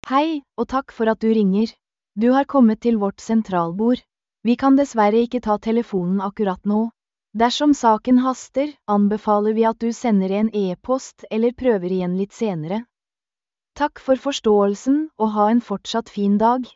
Lydmelding Eksempler
Her er noen eksempler på lydmeldinger som kan brukes med tekst-til-tale.
Generisk_lydmelding.mp3